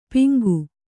♪ piŋgu